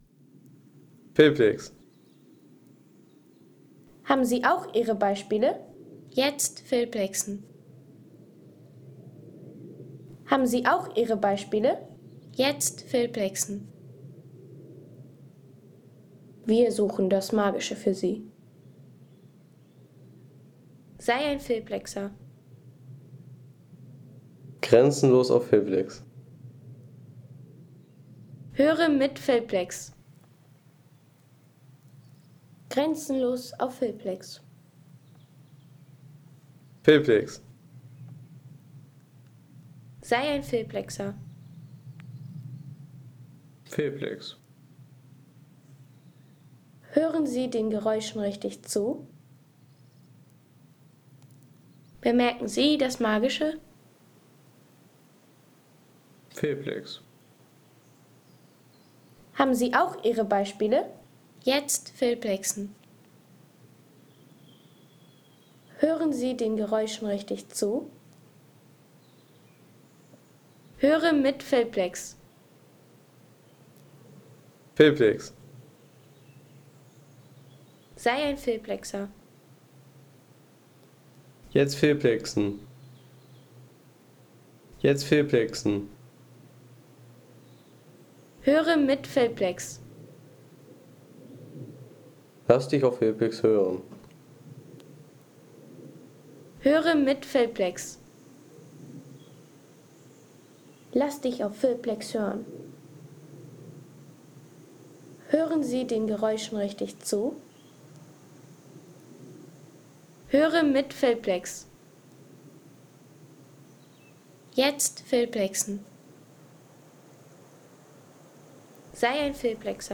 Erholungsgenuss für alle, wie z.B. diese Aufnahme: Häntzschelstiege im Elbsandsteingebirge
Landschaft - Berge